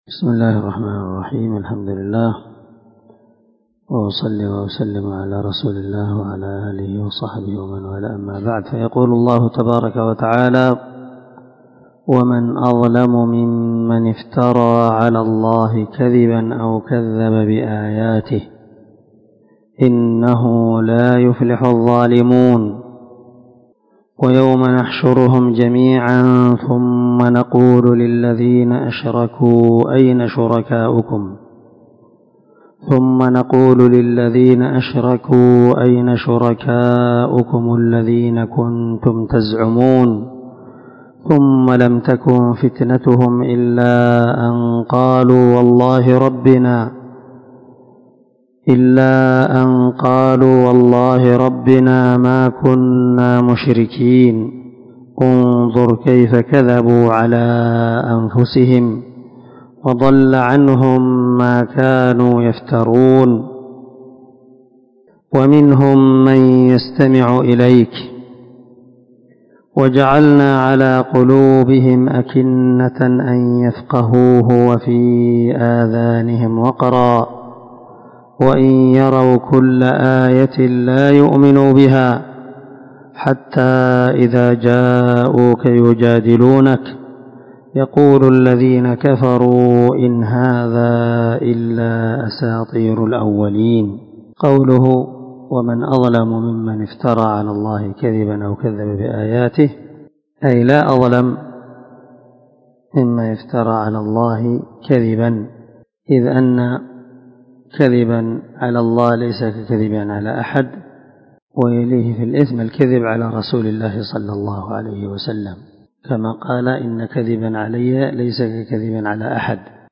398الدرس 6 تفسير آية ( 21 – 25 ) من سورة الأنعام من تفسير القران الكريم مع قراءة لتفسير السعدي